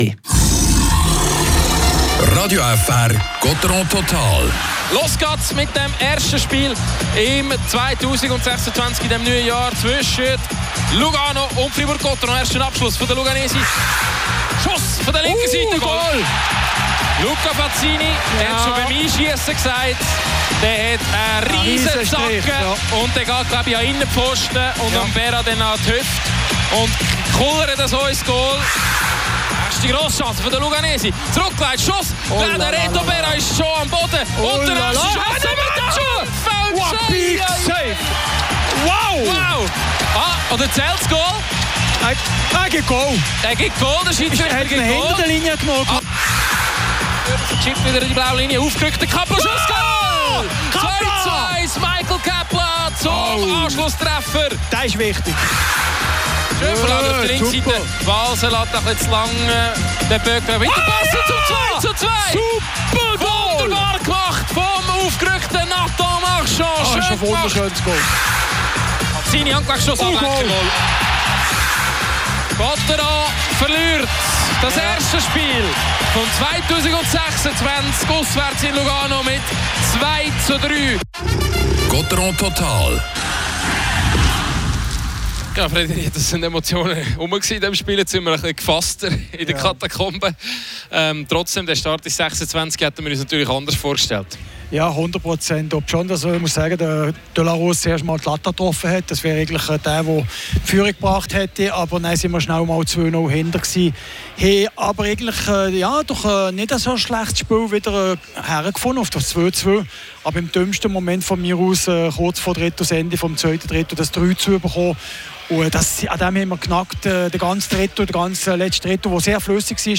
Die Highlights des Spiels, die Interviews